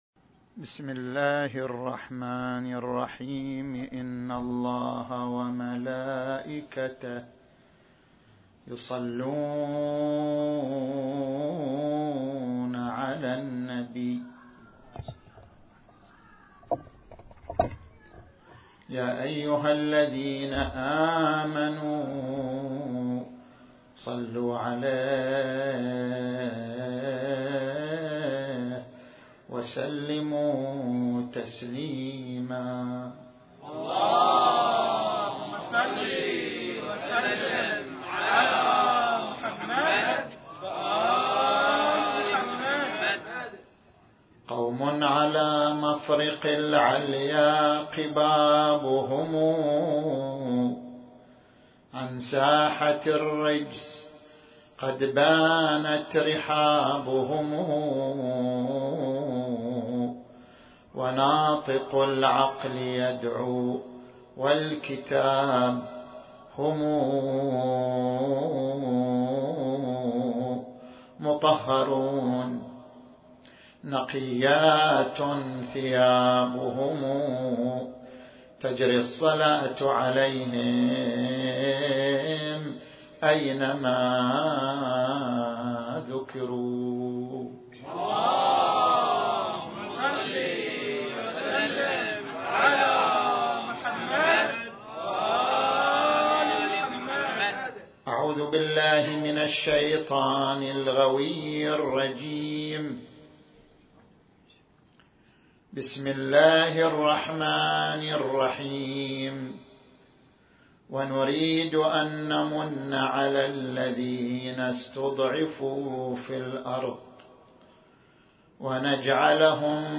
المكان: مأتم الحاج أبو أنور آل اوحيد - الربيعية